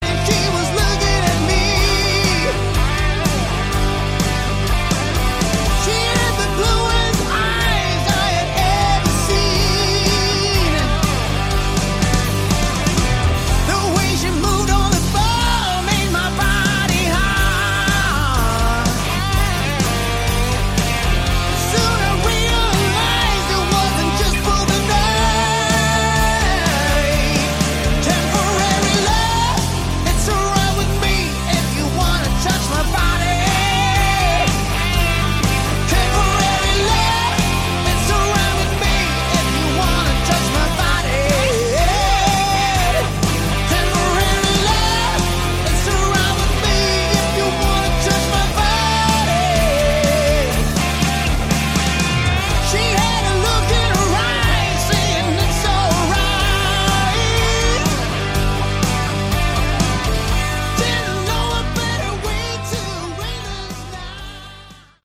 Category: Melodic Rock
vocals
bass
keyboards
lead and rhythm guitar
drums